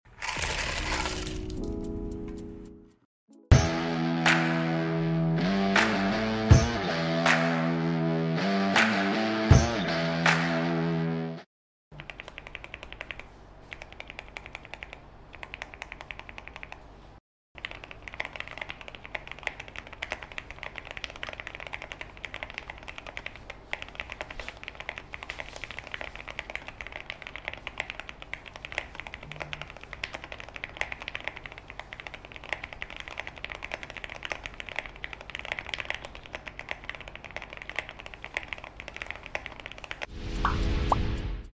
Sound Test Switch Gateron Oil Sound Effects Free Download